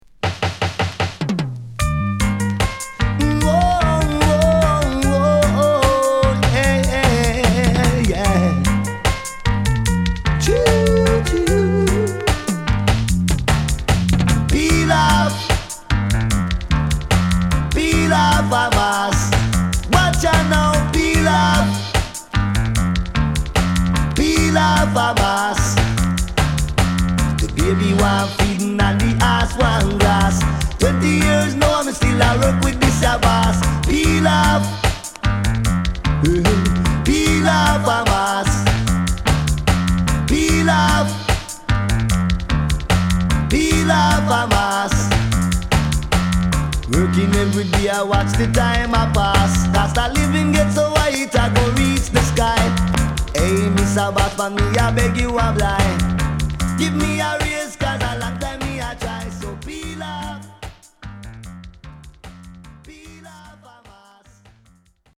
HOME > Back Order [DANCEHALL LP]
SIDE B:盤質は少しチリノイズ入りますが良好です。